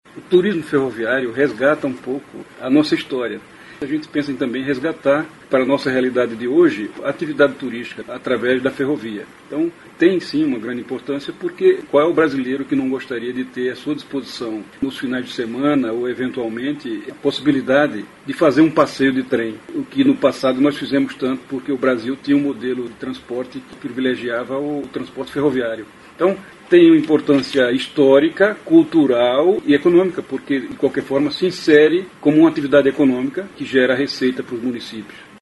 aqui para ouvir declaração do secretário Neusvaldo Ferreira sobre a importância do investimento no setor.